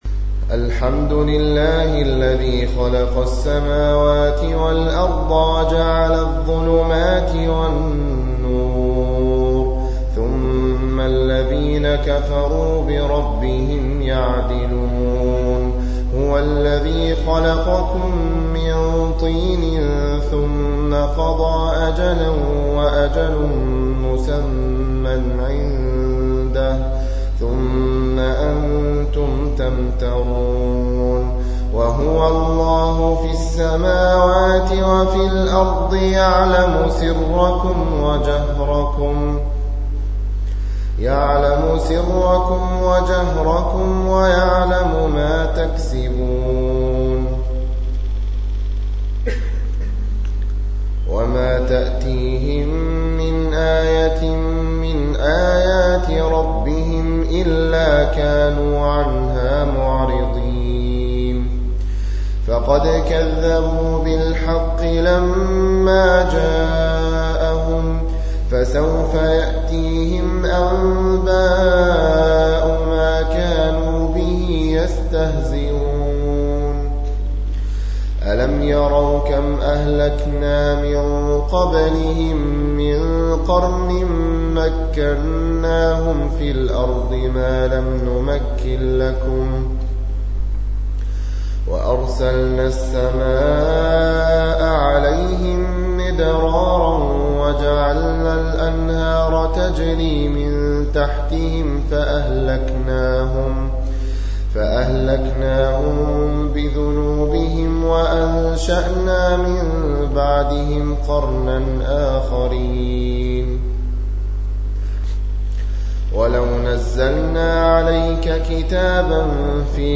الصنف: تلاوات
رواية : حفص عن عاصم